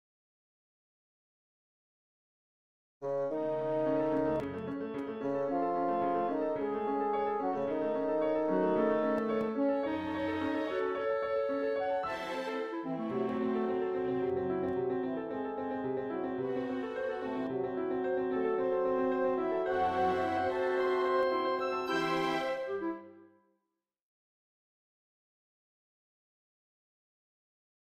On entend toujours la thème A mais cette fois légèrement varié et divisé entre deux pupitres : bassons puis clarinettes tout d’abord, cor puis groupe basson hautbois flûtes ensuite.
La tonalité est alors fa dièse mineur.
Chiffre 12 thème aux bassons puis clarinettes en fa dièse mineur.